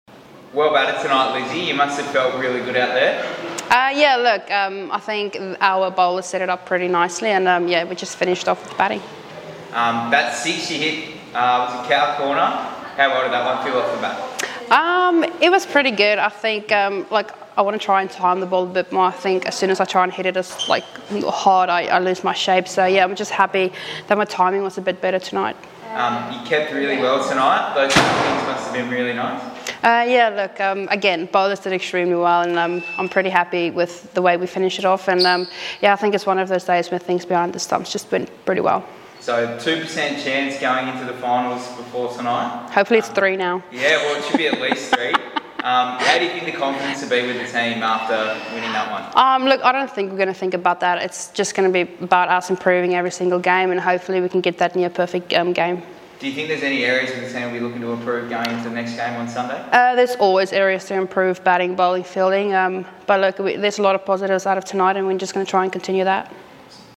Hobart Hurricanes Wicket-Keeper and Opening Batsman, Lizelle Lee, following the Hurricanes 8 wicket win over the Stars.